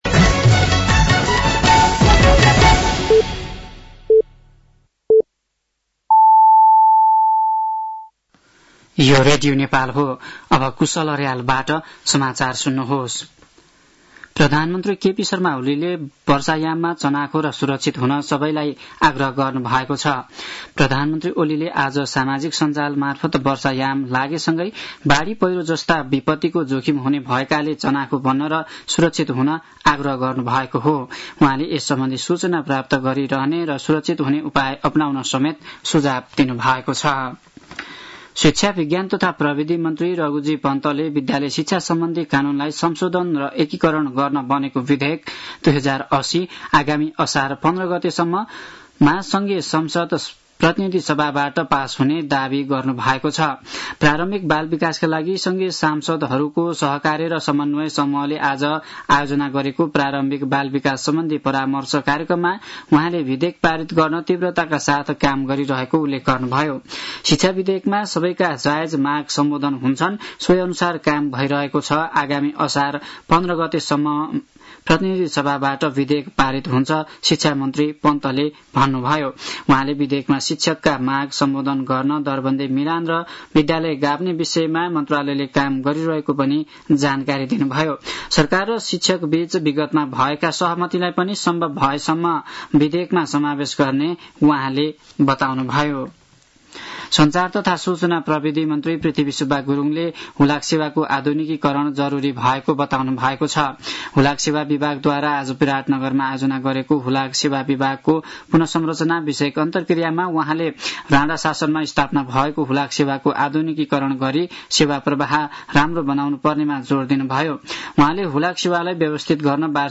साँझ ५ बजेको नेपाली समाचार : २४ जेठ , २०८२
5.-pm-nepali-news-.mp3